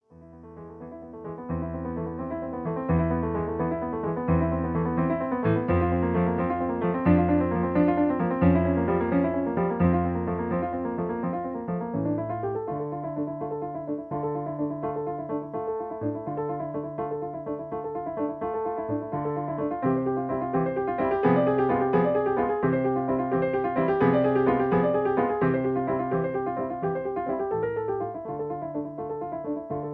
Original Key. Piano Accompaniment